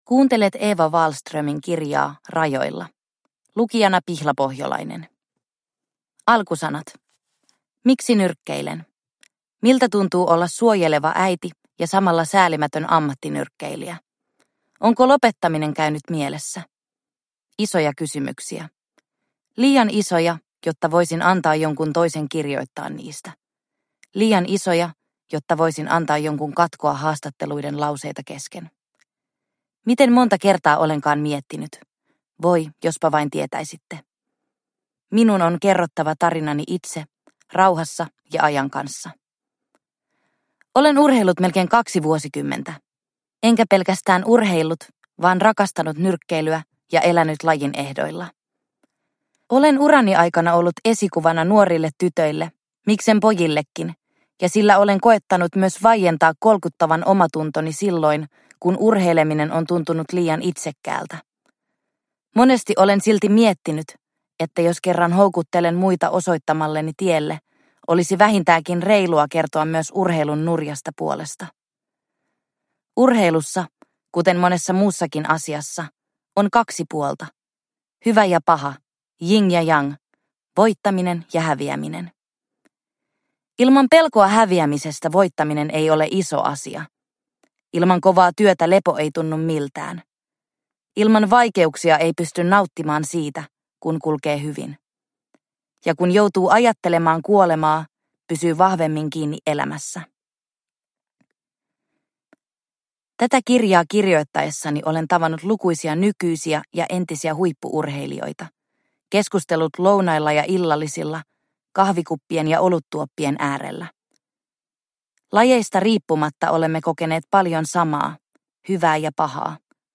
Rajoilla – Ljudbok – Laddas ner